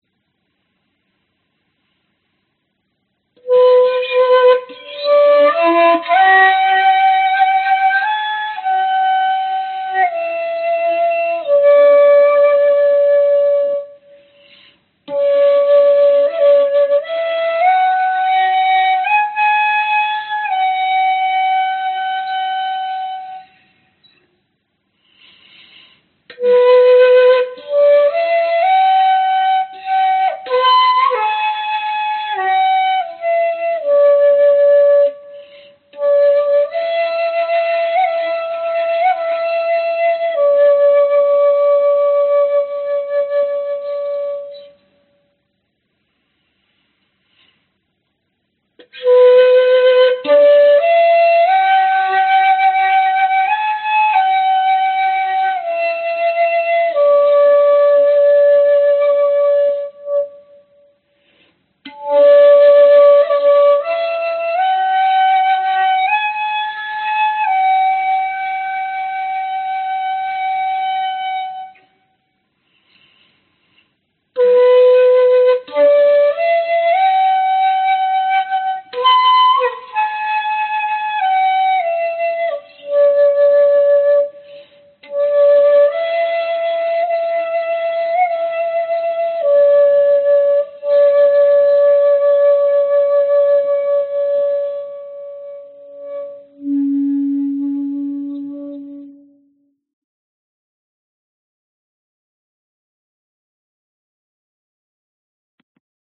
Tag: 日本 亚洲 摇篮曲 尺八 民间曲调 传统 器乐